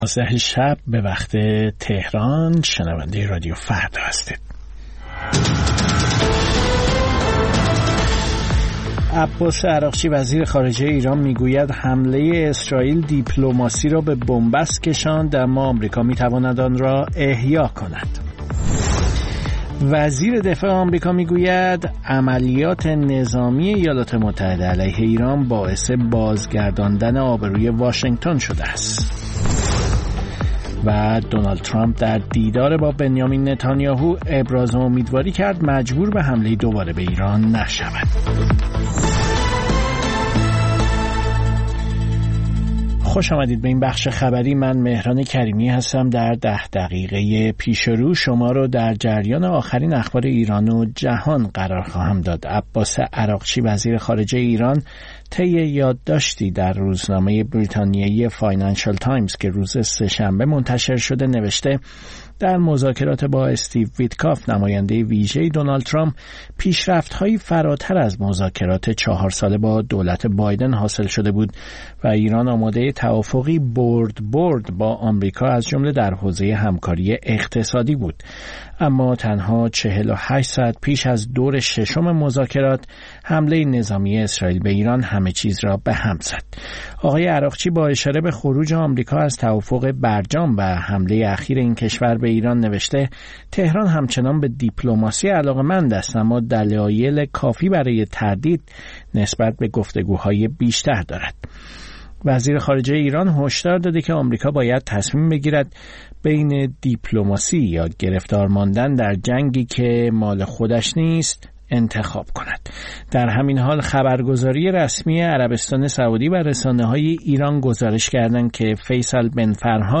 سرخط خبرها ۲۳:۰۰